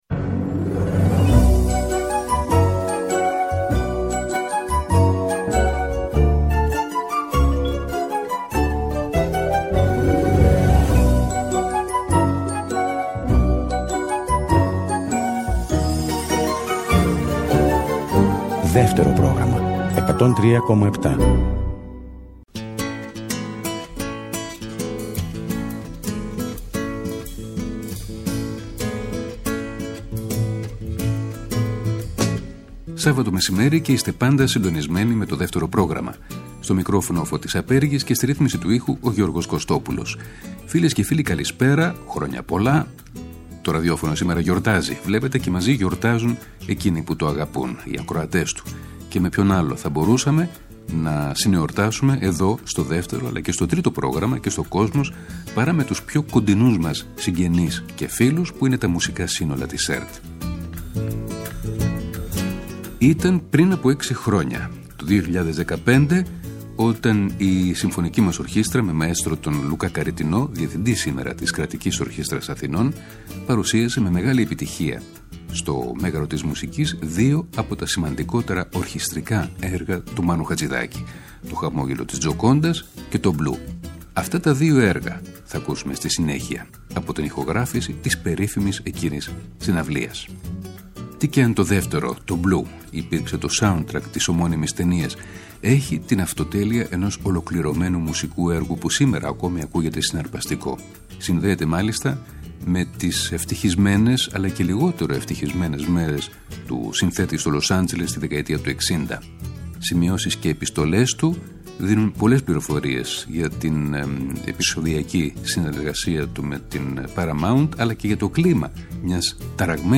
ορχηστρικά έργα